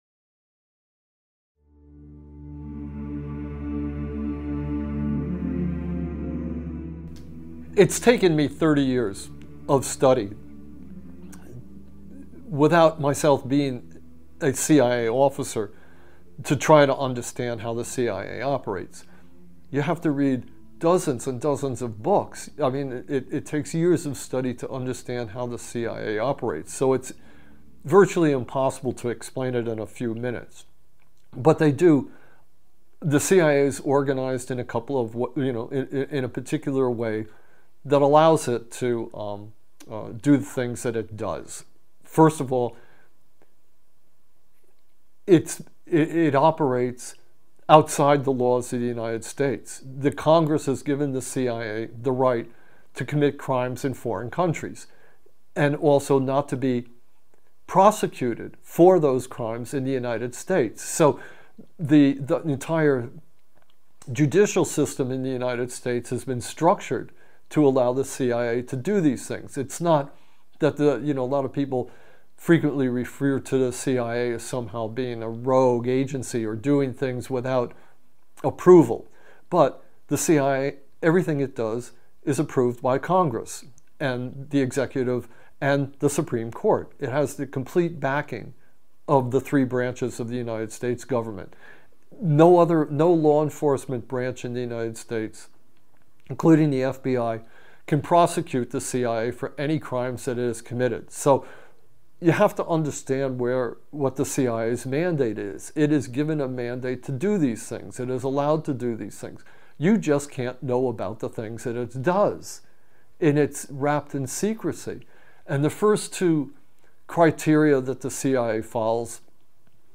This is Part 1 of a two part interview that paints the CIA as an organized crime syndicate serving the needs of the Capitalist Class.